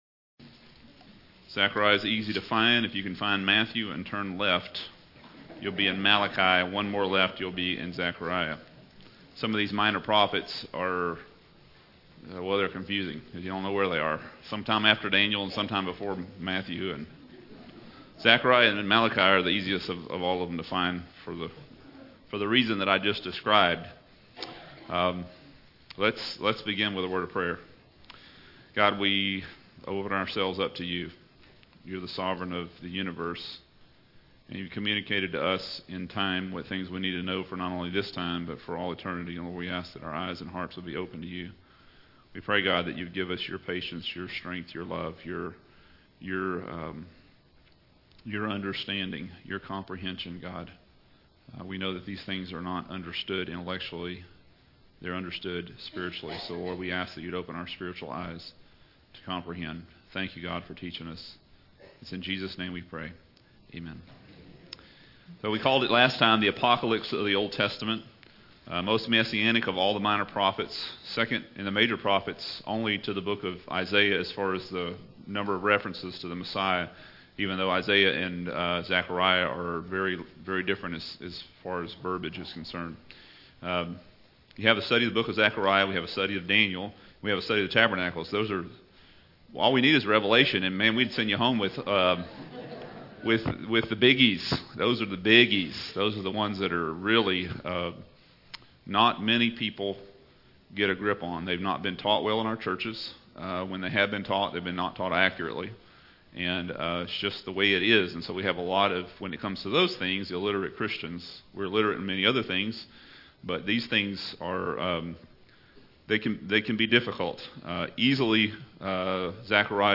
Zechariah-Lesson-2.mp3